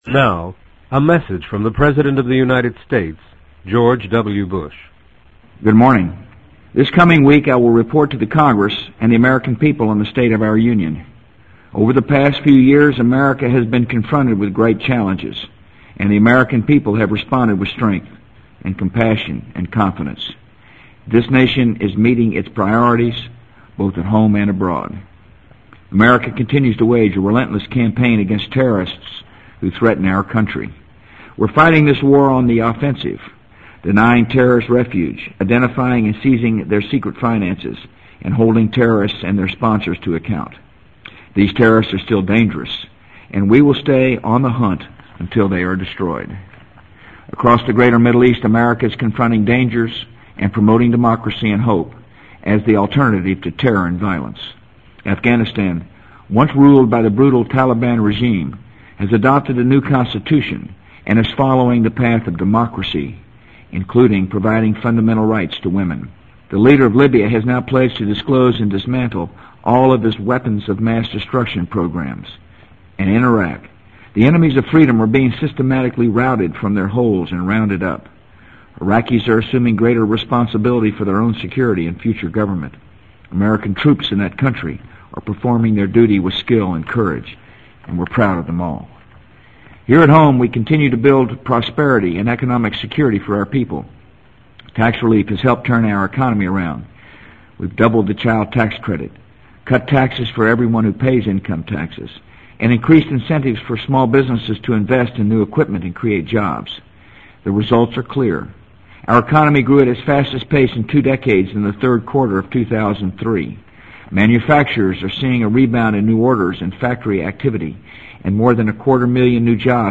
【美国总统George W. Bush电台演讲】2004-01-17 听力文件下载—在线英语听力室